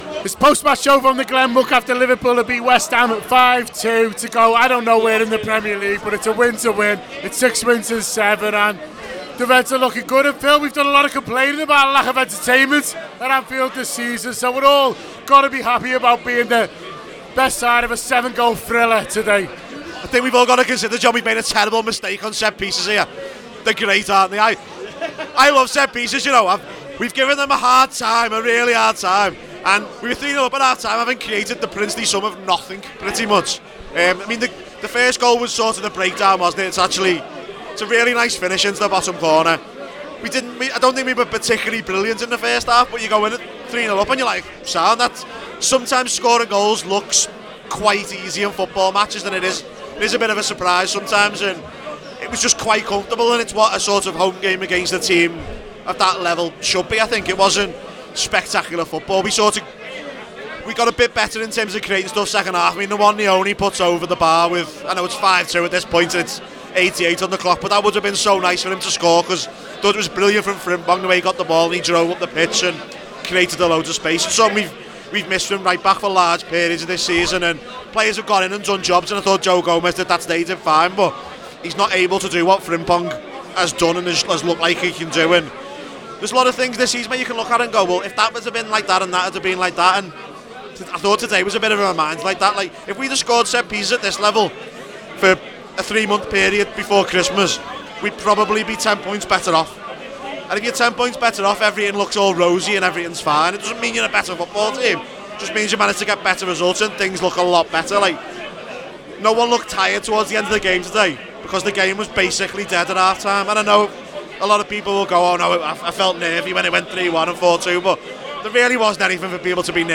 Below is a clip from the show – subscribe to The Anfield Wrap for more reaction to Liverpool 5 West Ham 2…